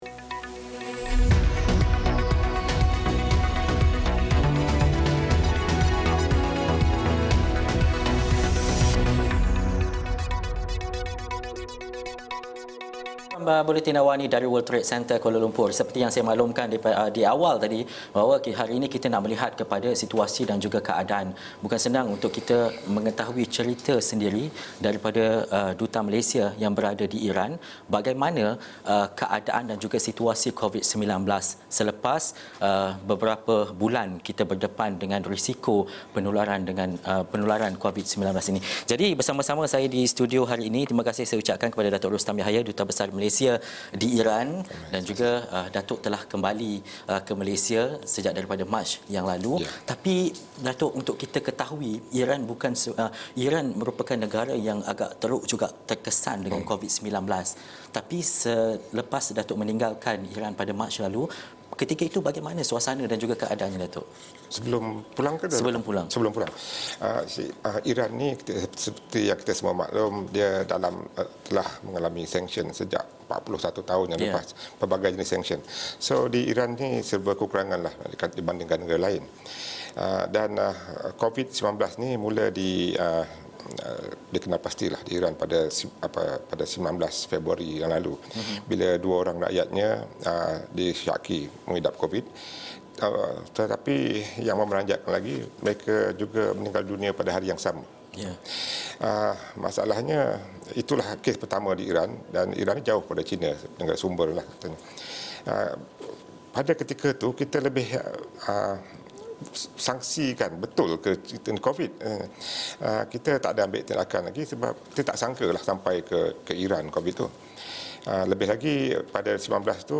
Temubual